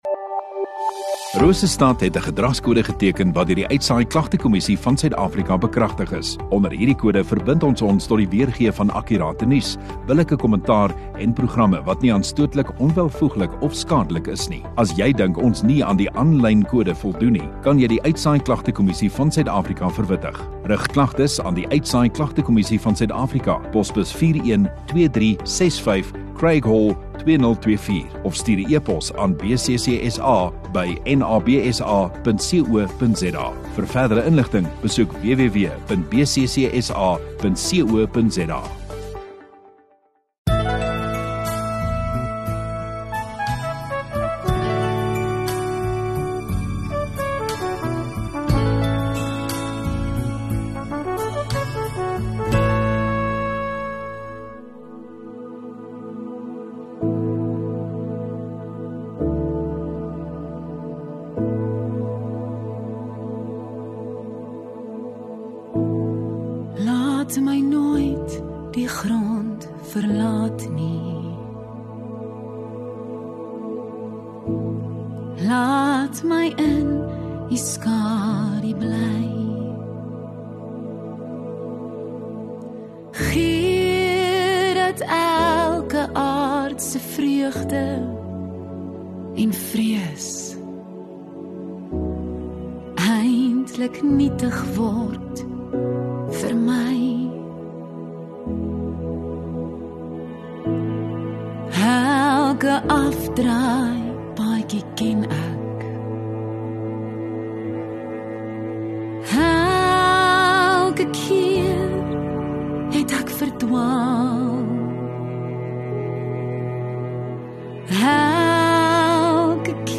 25 May Sondagoggend Erediens